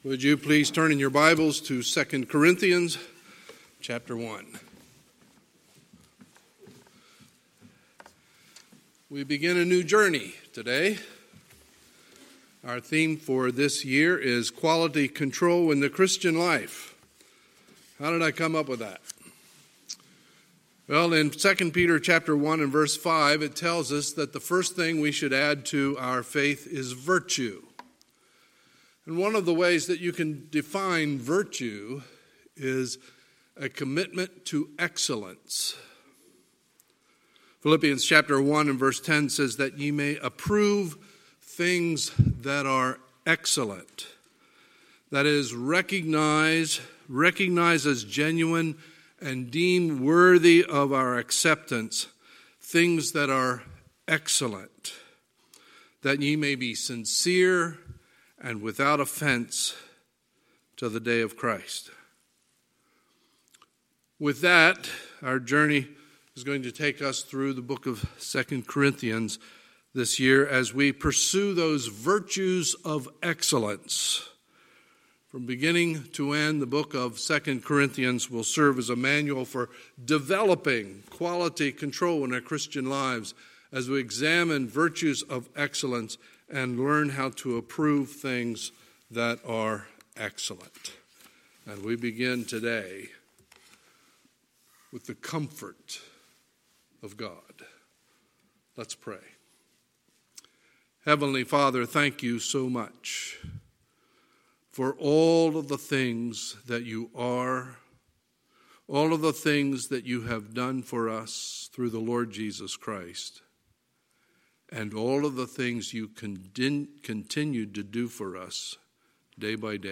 Sunday, January 5, 2020 – Sunday Morning Service